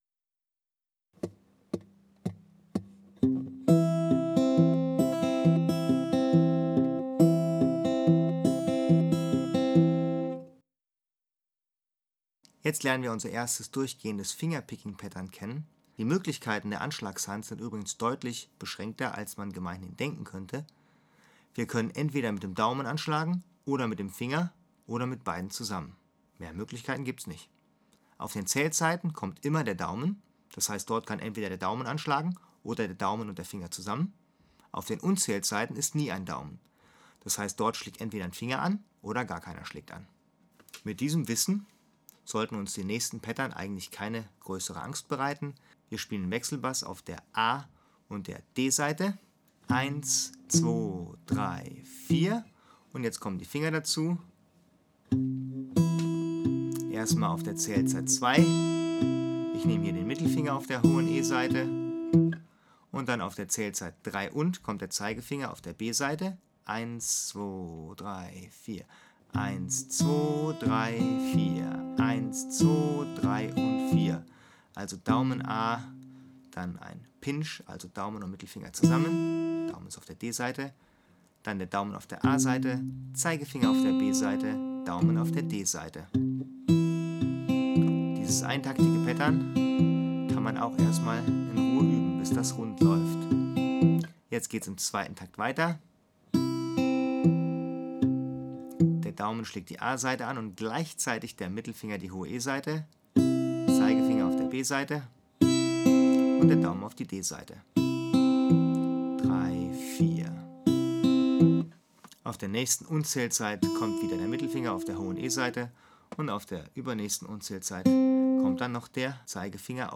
Lese- / Hörproben zu Garantiert Akustik Bluesgitarre Lernen
sound_clipping 096 – Zweitaktiges Picking-Pattern C
096-Zweitaktiges-Picking-Pattern-C.mp3